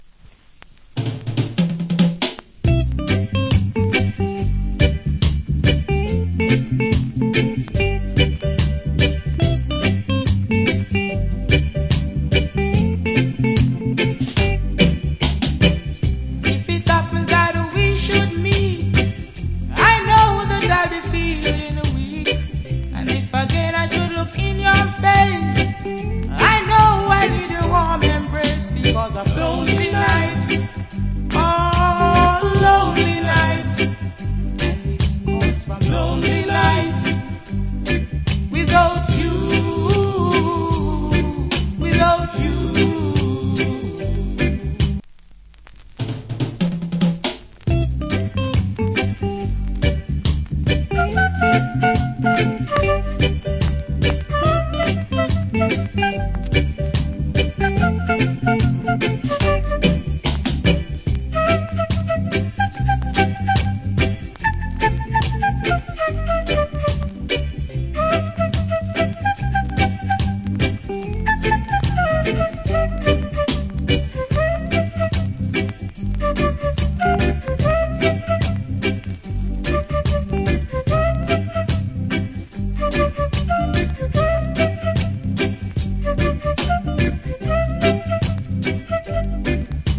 Nice Vocal.